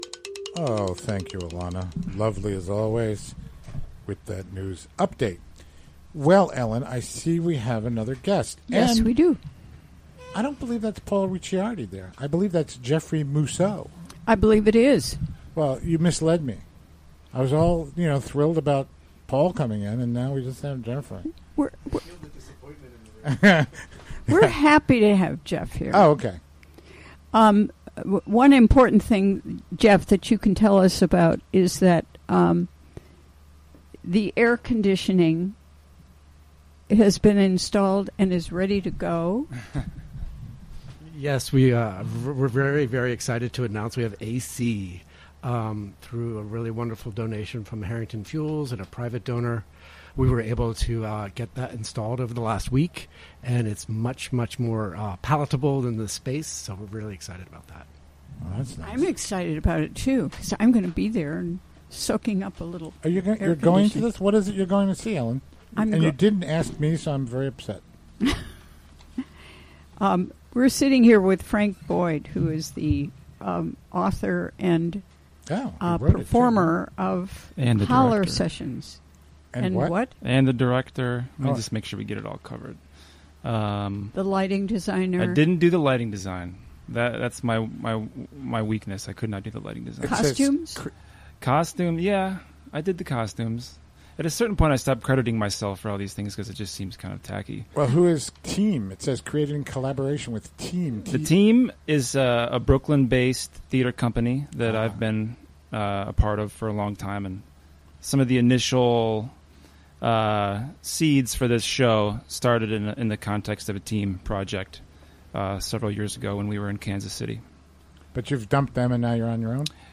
Recorded during the WGXC Afternoon Show Thursday, July 13, 2017.